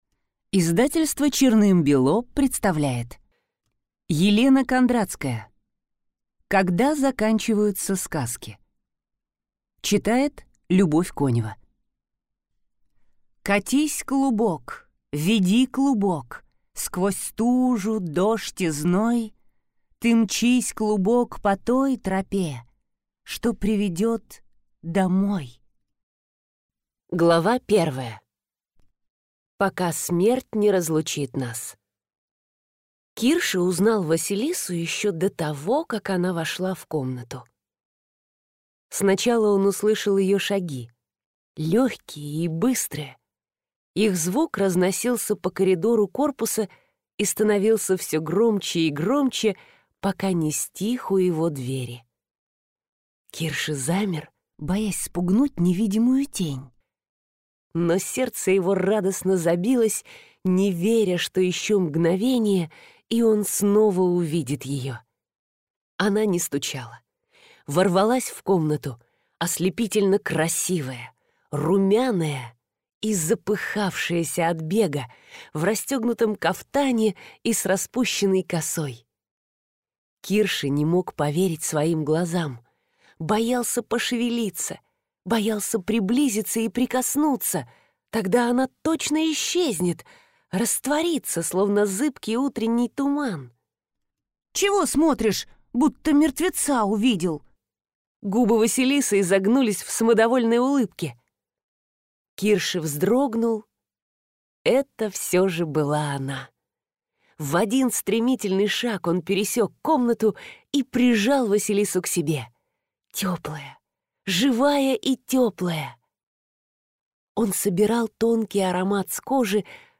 Аудиокнига Когда заканчиваются сказки | Библиотека аудиокниг